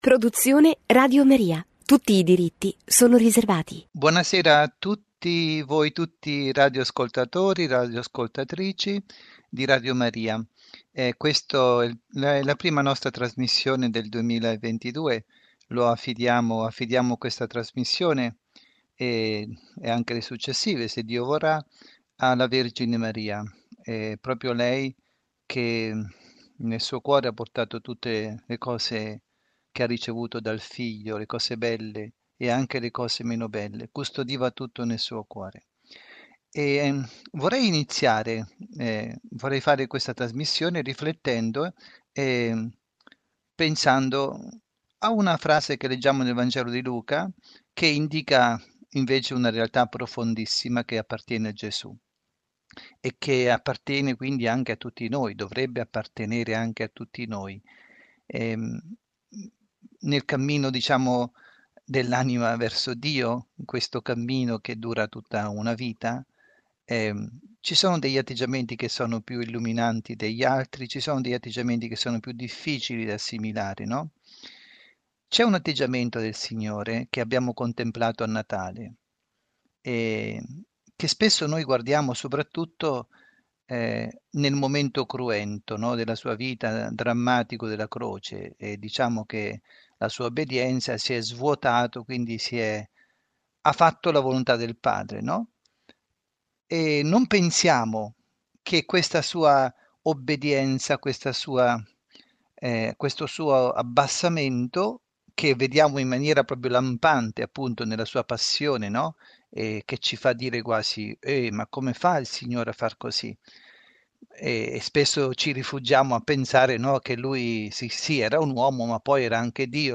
Firenze, Comunità del Carmine 16 dicembre 2017